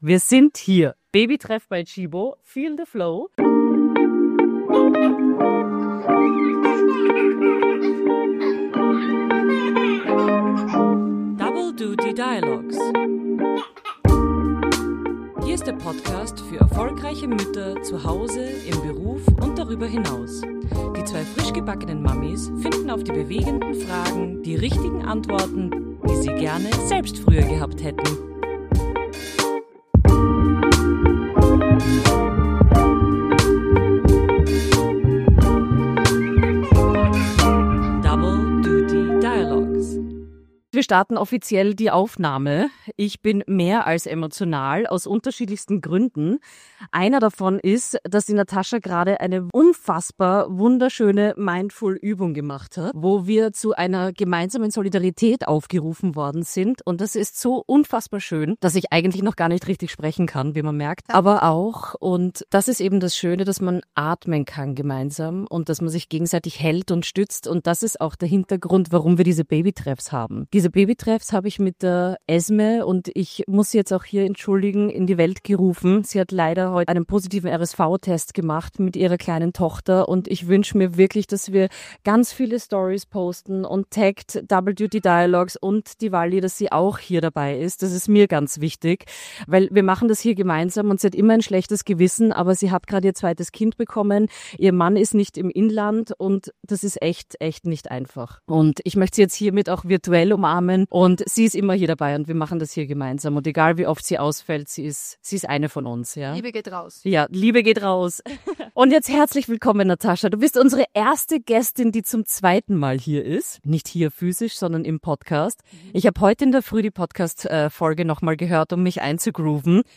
In dieser Folge starten wir beim Babytreff by Tchibo